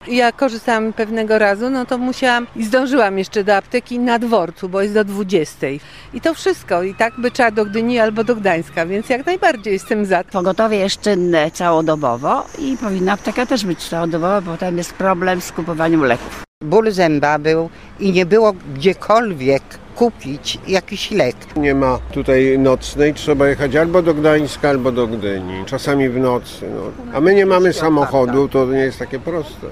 Posłuchaj wypowiedzi mieszkańców:
apteka_sopot_sonda.mp3